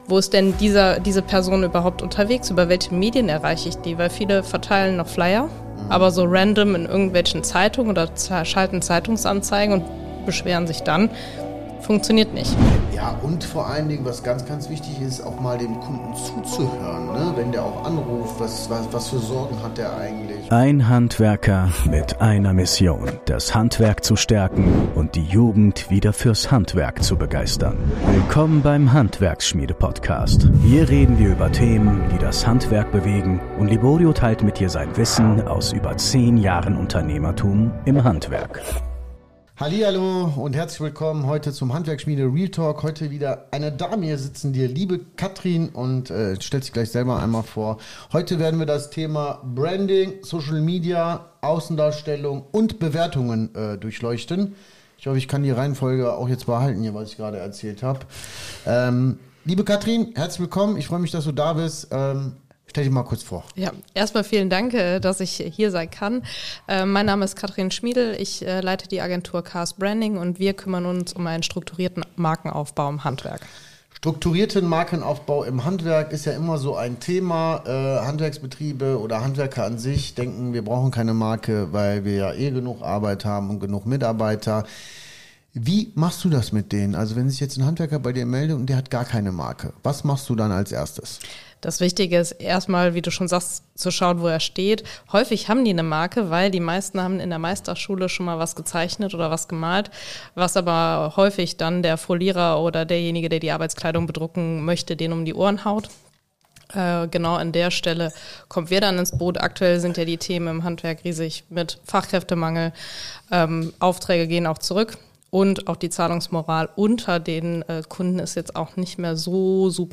Die Macht von Branding und Kundenbewertungen für Handwerksbetriebe | Interview